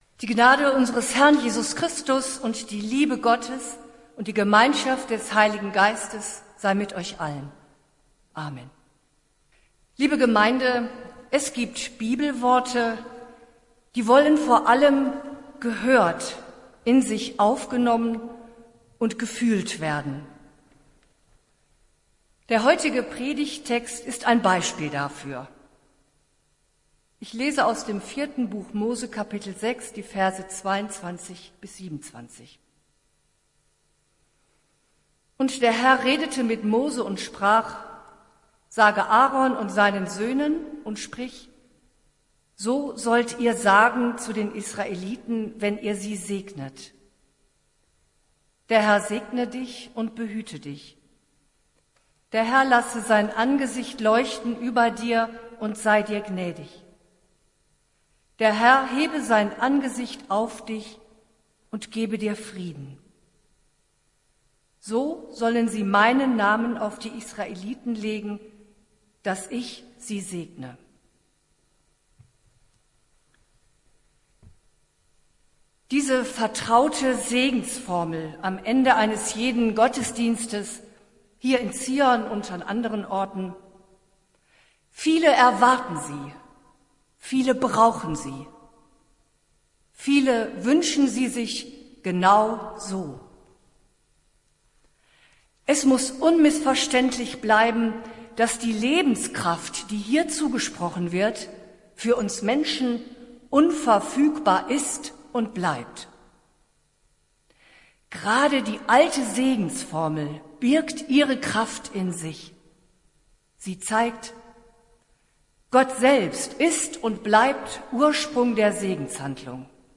Predigt des Gottesdienstes vom 07.06.2020
Wir haben uns daher in Absprache mit der Zionskirche entschlossen, die Predigten zum Download anzubieten.